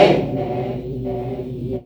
1906L CHANT.wav